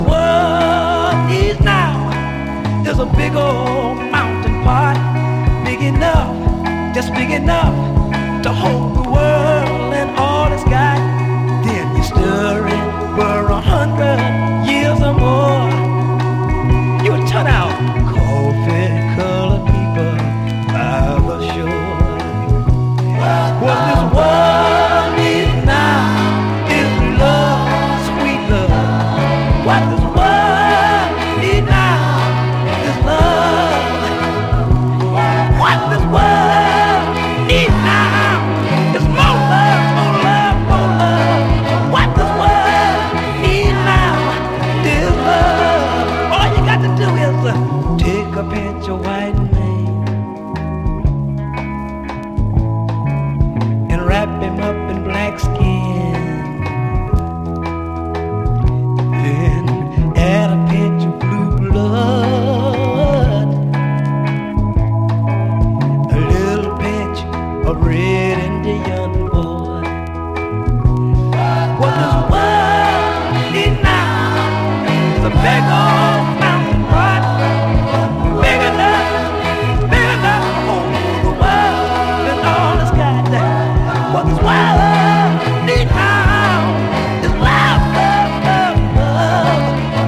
牧歌的なサウンドスケープを聴かせる